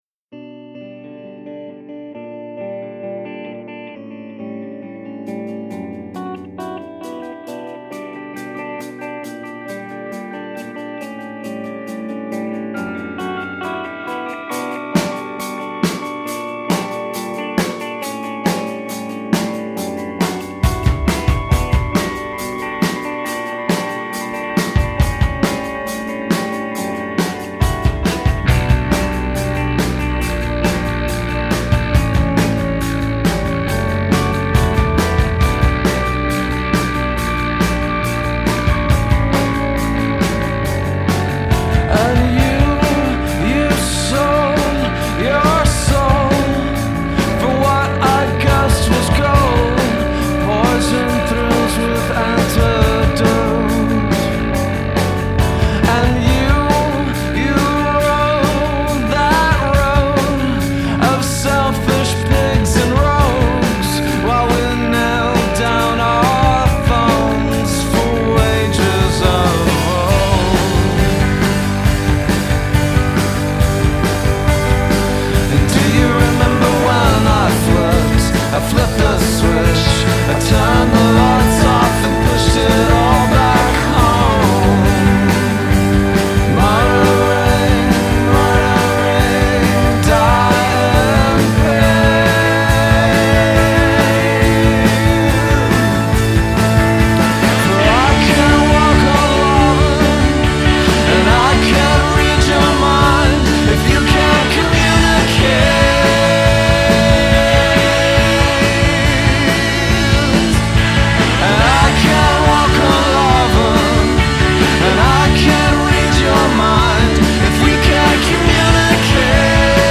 Dallas based “indie rock heroes